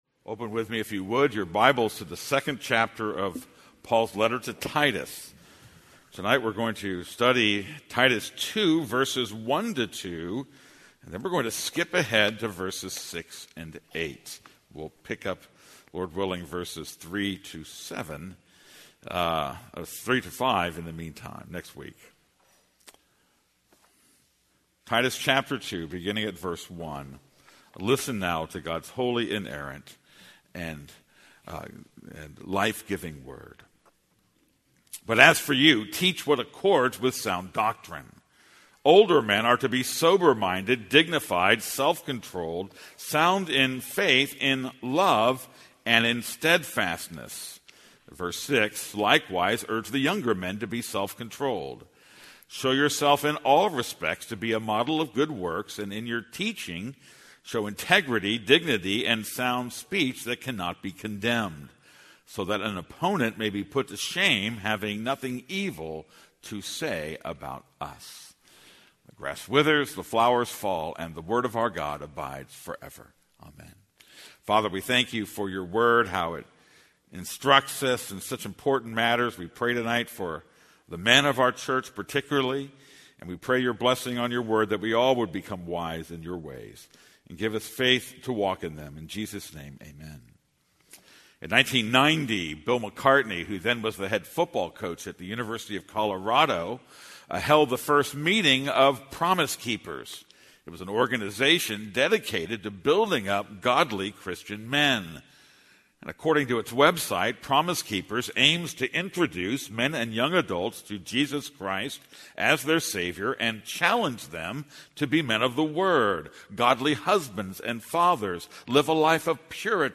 This is a sermon on Titus 2:1-2; 6-8.